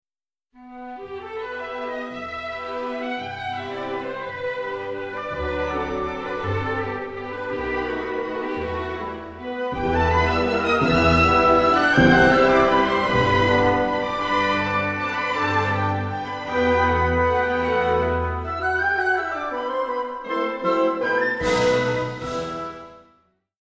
Allegro ma non troppo, mm.1-17